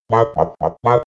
COG_VO_question.ogg